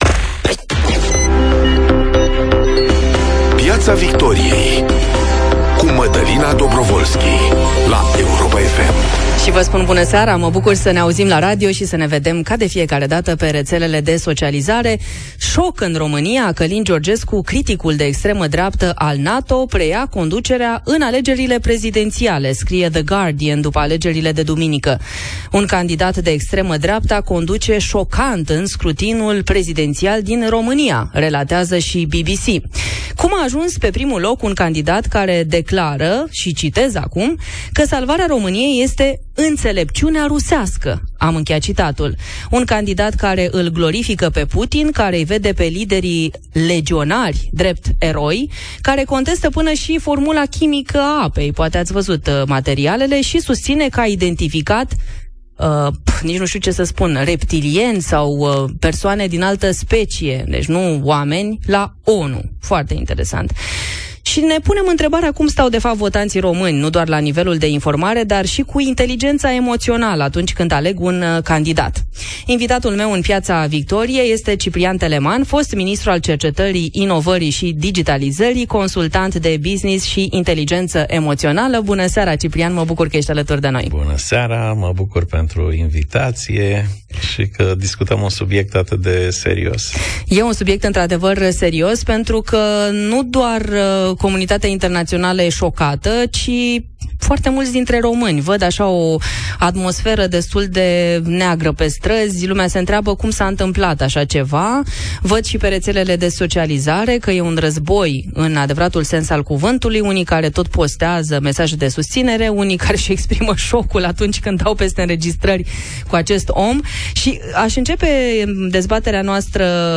Ioana Ene Dogioiu vorbește cu invitatul său, Dragoș Pîslaru, președintele REPER și fost ministru al muncii, despre noua lege a pensiilor, inechitățile din sistemul pensiilor, pregătirile pentru anul electoral 2024.